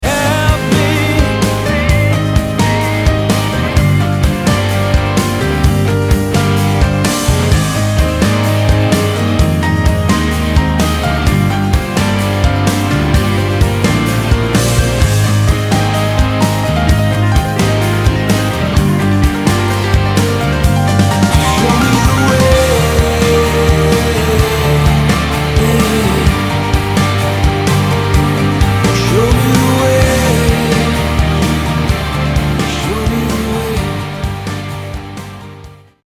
piano/keys/string arrangements